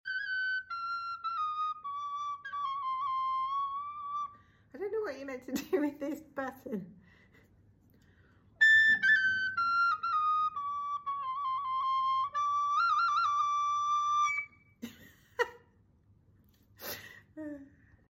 The Georgian salamuri.
Originally made of bone, this one is wooden! Sometimes 2 are played together at the same time, one in each hand 🎉.